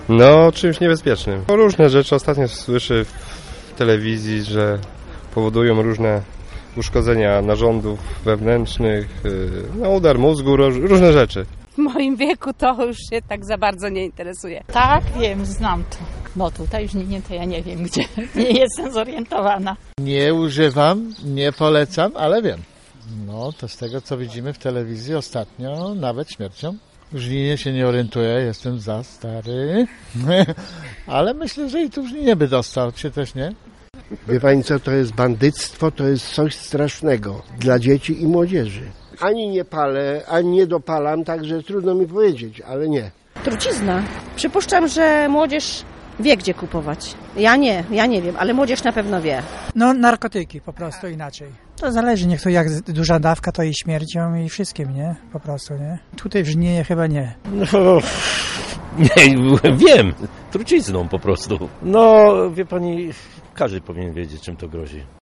Sprawdziliśmy co mieszkańcy Pałuk wiedzą na temat dopalaczy- czym są i czym grozi ich zażywanie?